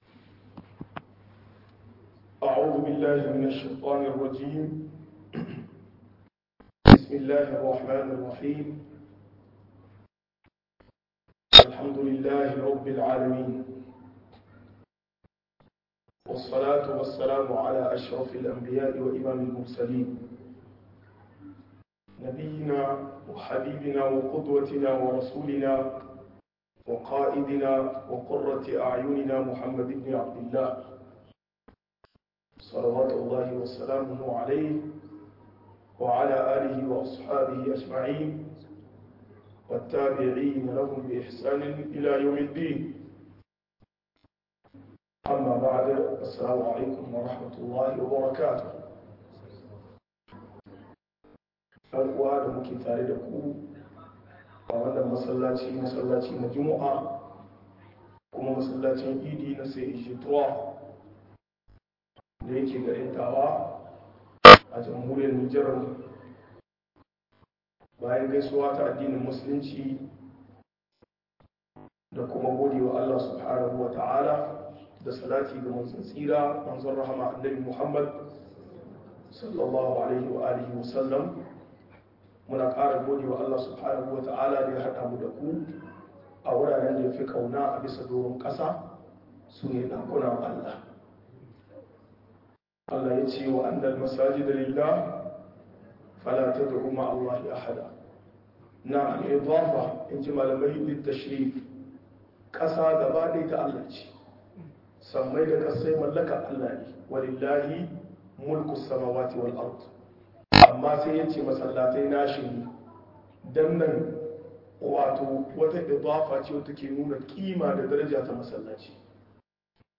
Yaya zamu tarbiyyar yara akan haƙuri - MUHADARA